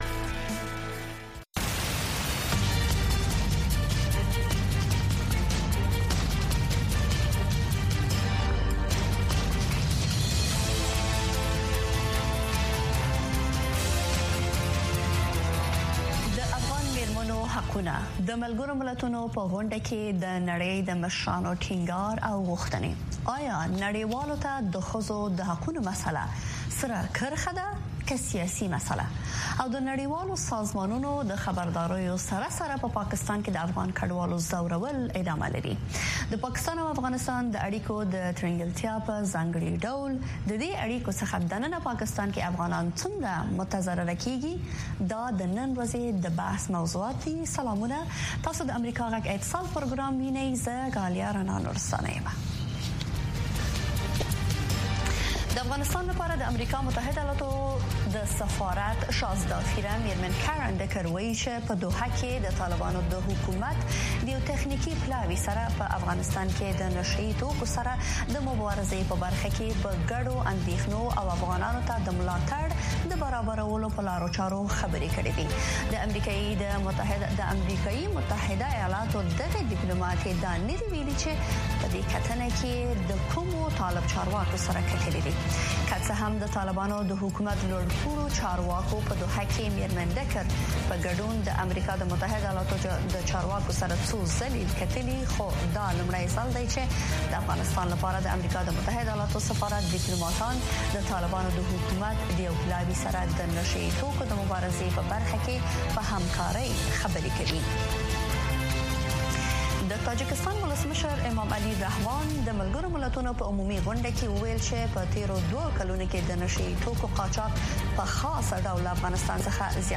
Listen Live - رادیویي خپرونې - امریکا غږ
په دې خپرونه کې د چارواکو، شنونکو او خلکو سره، مهمې کورنۍ او نړیوالې سیاسي، اقتصادي او ټولنیزې مسئلې څېړل کېږي. دغه نیم ساعته خپرونه له یکشنبې تر پنجشنبې، هر مازدیګر د کابل پر شپږنیمې بجې، په ژوندۍ بڼه خپرېږي.